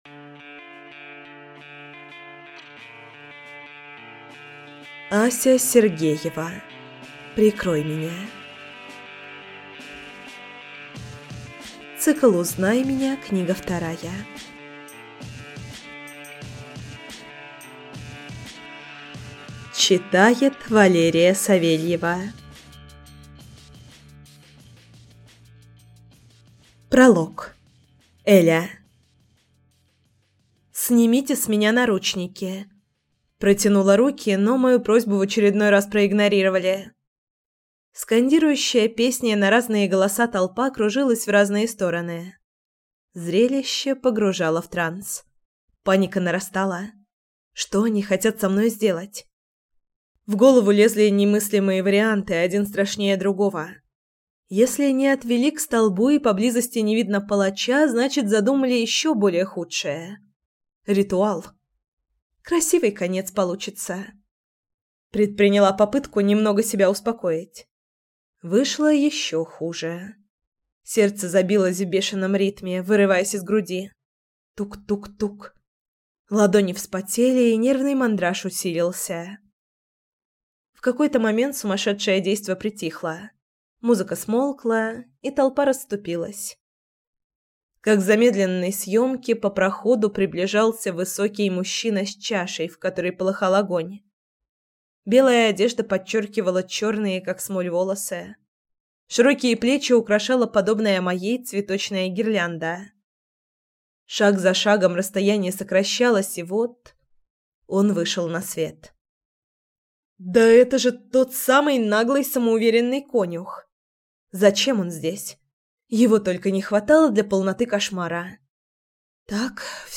Аудиокнига Прикрой меня | Библиотека аудиокниг